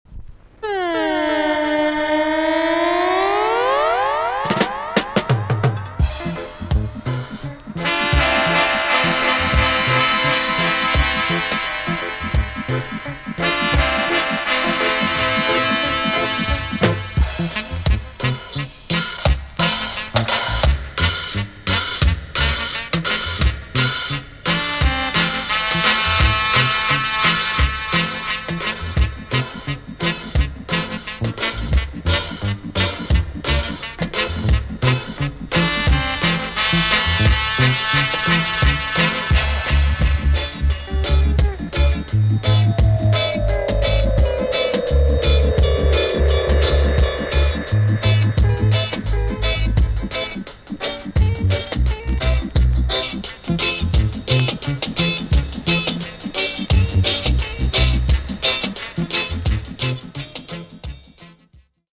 B.SIDE EX- 音はキレイです。
B.SIDEはVOCALなしのROOTS好きには堪らないよだれ物のDUB WISEな内容!!